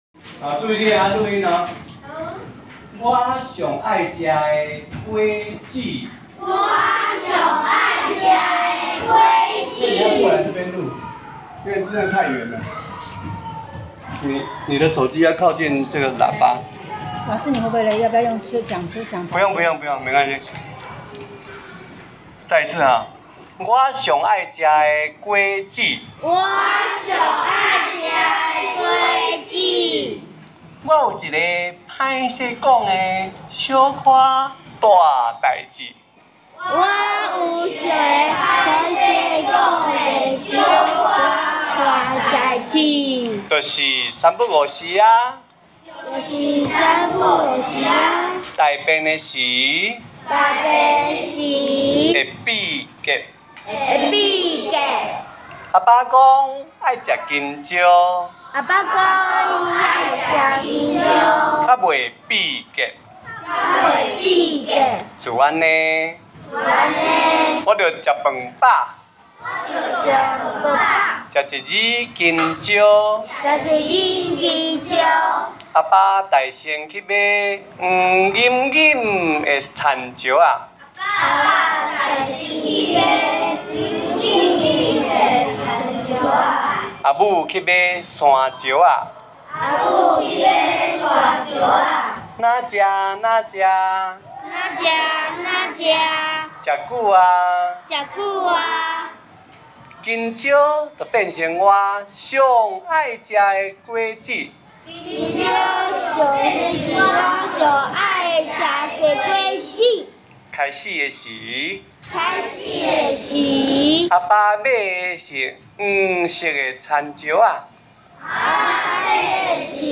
108學年度新和國小多語文競賽-閩南語朗讀聽力練習檔案